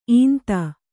♪ īnta